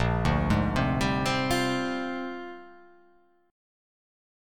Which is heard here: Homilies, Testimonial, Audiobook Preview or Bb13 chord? Bb13 chord